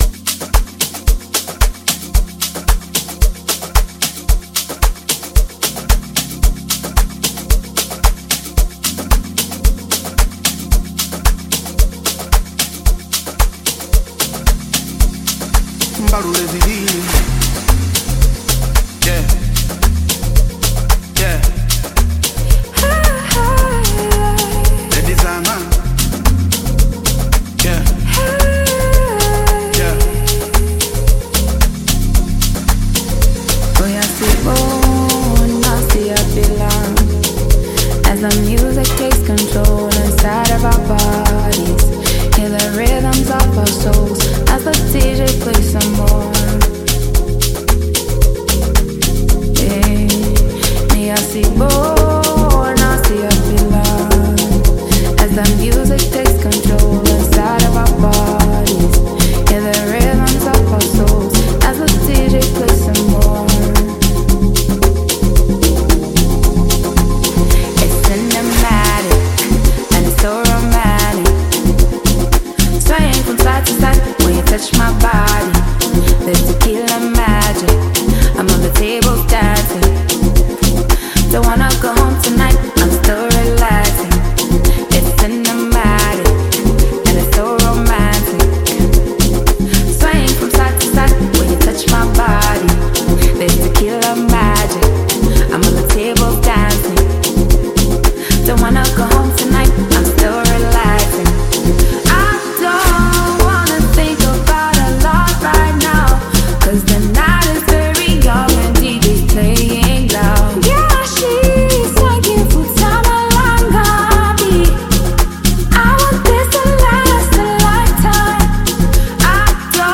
Backed by a mellow yet rhythmic production